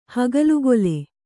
♪ hagalugole